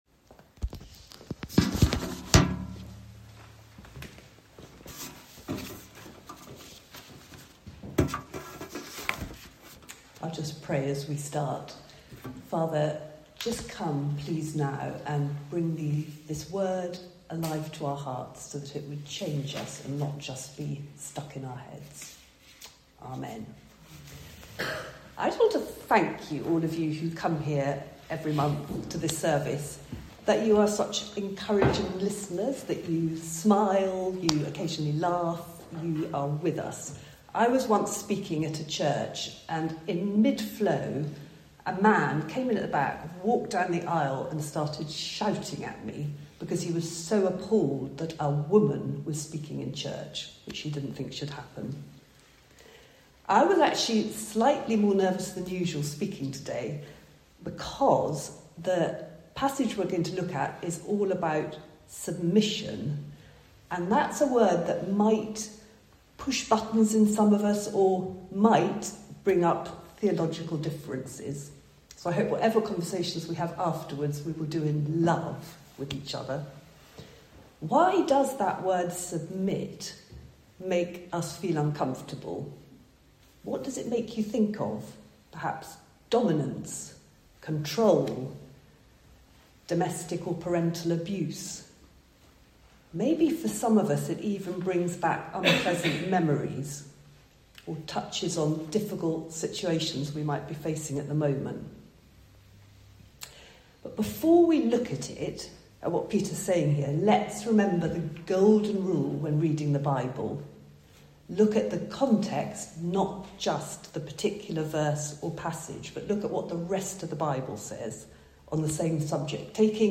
The recording of this month’s Valley Worship can be listened to below